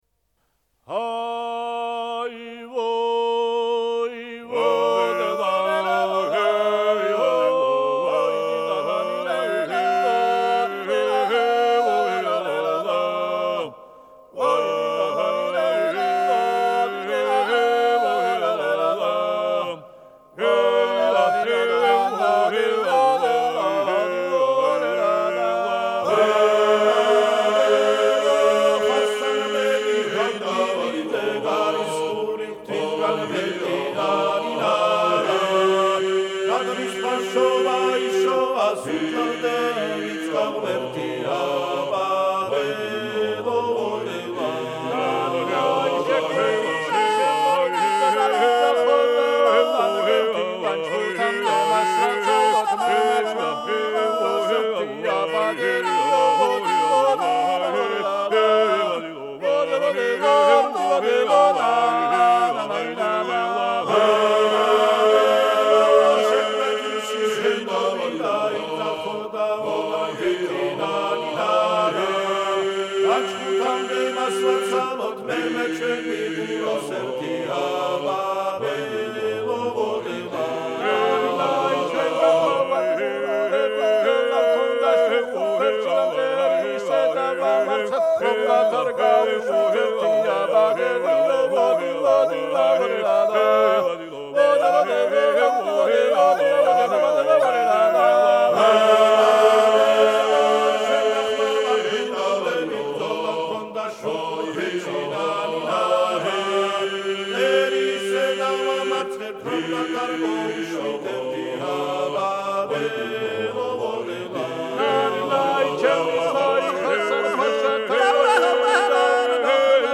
Трек размещён в разделе Русские песни / Грузинские песни.